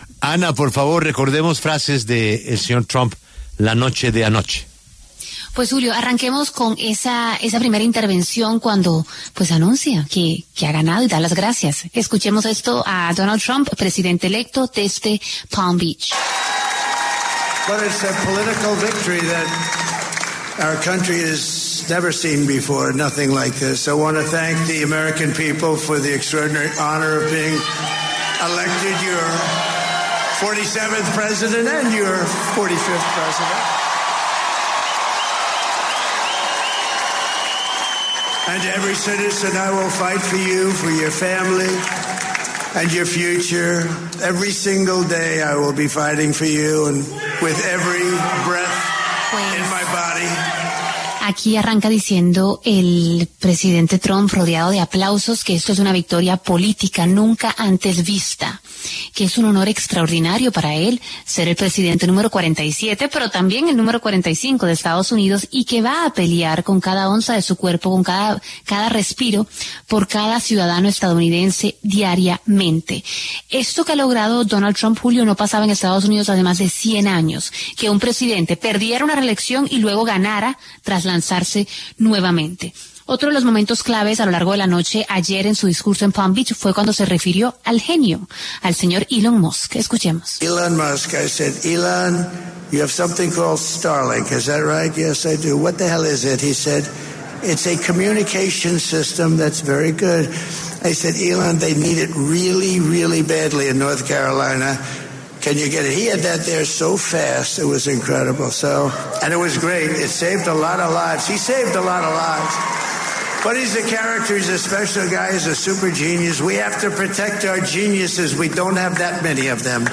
Después de proclamarse ganador de las elecciones en Estados Unidos y confirmar su regreso a la Casa Blanca, el exmandatario Donald Trump aseguró desde el centro de convenciones de Palm Beach que gobernará para todos y que “juntos” harán un país mejor.
En su discurso, el cual pronunció acompañado de su familia y directivos de su campaña, el expresidente Trump utilizó un tono conciliador e invitó a dejar “atrás las divisiones de los últimos años”.